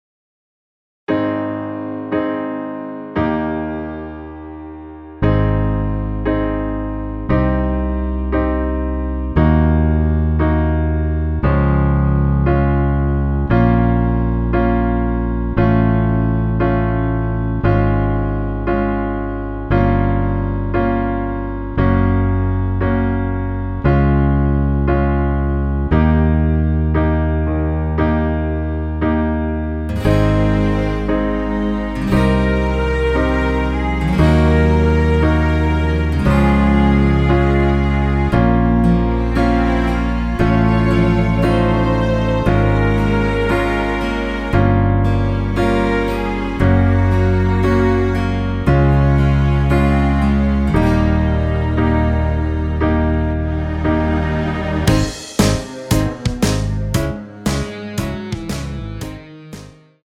원키에서(-1)내린 MR입니다.
Ab
앞부분30초, 뒷부분30초씩 편집해서 올려 드리고 있습니다.